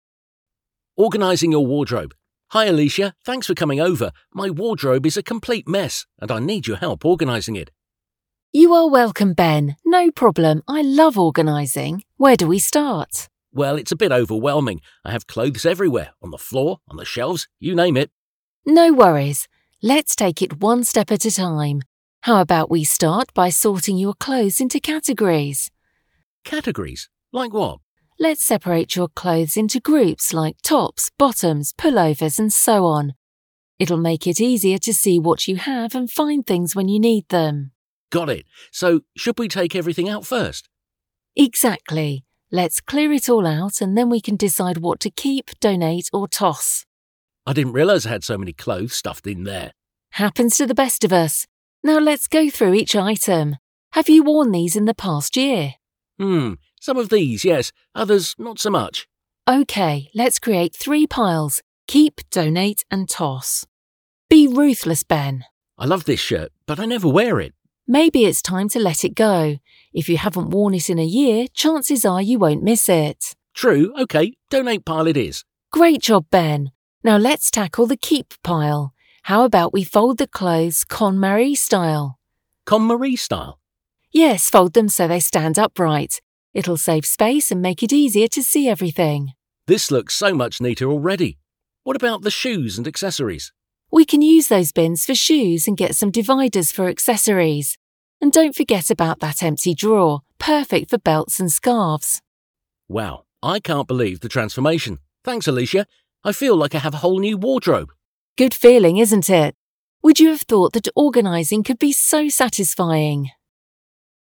5-ORGANISING-YOUR-WARDROBE-Dialogue.mp3